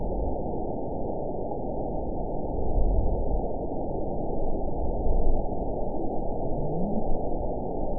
event 912175 date 03/19/22 time 21:26:00 GMT (3 years, 2 months ago) score 9.43 location TSS-AB01 detected by nrw target species NRW annotations +NRW Spectrogram: Frequency (kHz) vs. Time (s) audio not available .wav